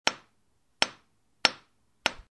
На этой странице собраны разнообразные звуки гвоздя: забивание, удары по металлу, скрип и другие эффекты.
Звук удару молотком по цементній стіні або цеглі